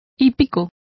Complete with pronunciation of the translation of equestrian.